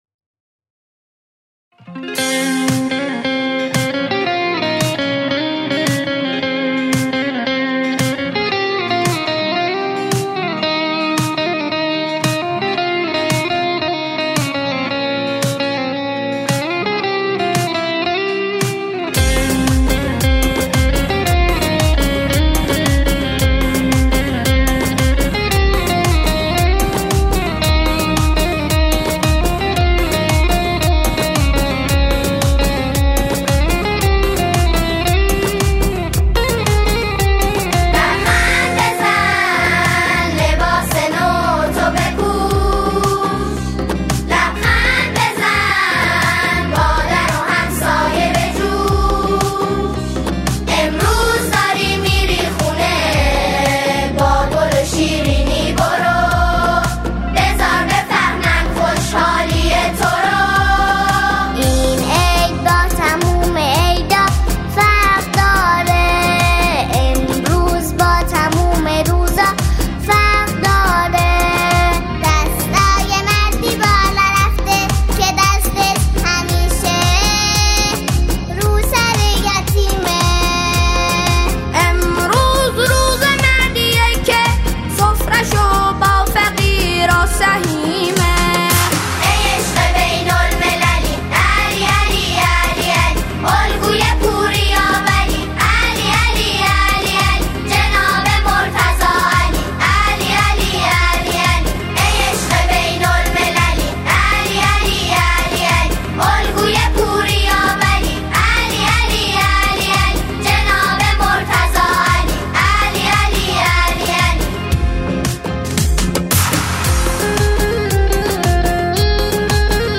با همخوانی پرشور اعضای گروه
در قالب شعری شاد و جشن‌گونه
ژانر: سرود